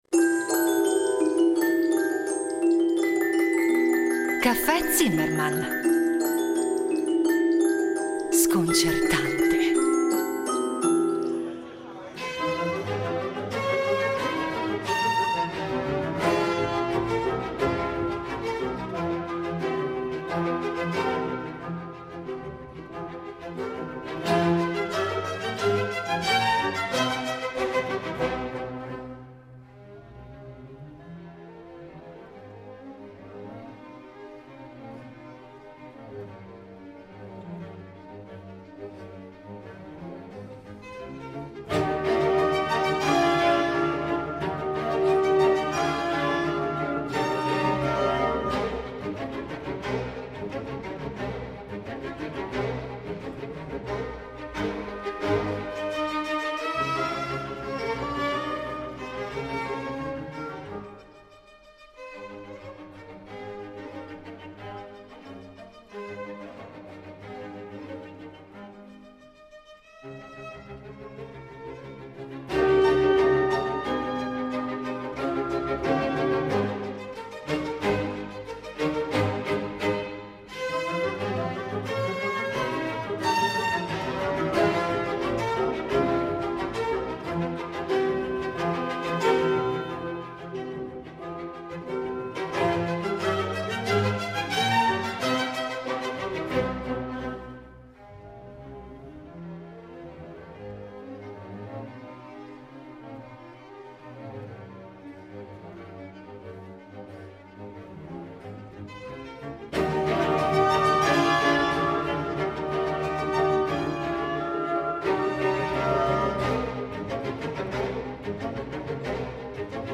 A dirigere Il Giardino Armonico dal 1989 Giovanni Antonini , tra i membri fondatori, che ai microfoni di Rete Due ne ripercorre la storia.
A guidarci le musiche della rilevante quanto varia discografia dell’ensemble (proposta in ordine cronologico).